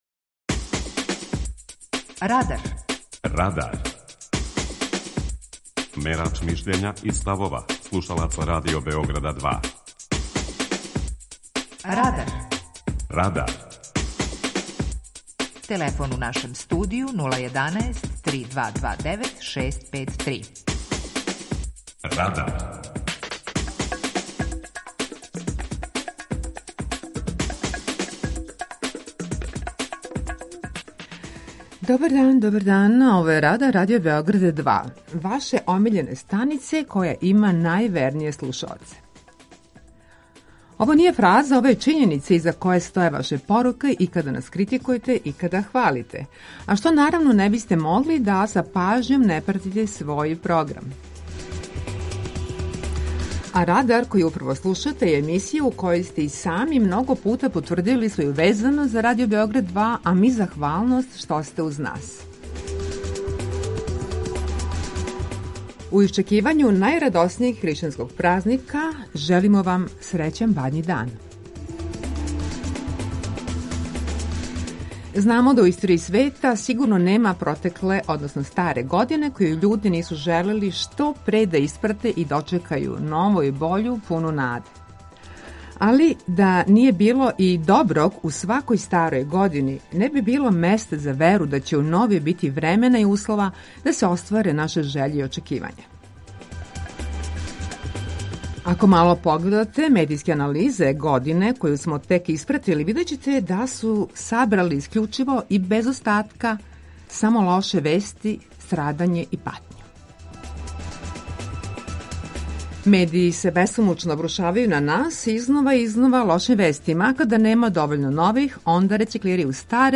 Питање Радара: Шта је било добро у 2022. години? преузми : 17.98 MB Радар Autor: Група аутора У емисији „Радар", гости и слушаоци разговарају о актуелним темама из друштвеног и културног живота.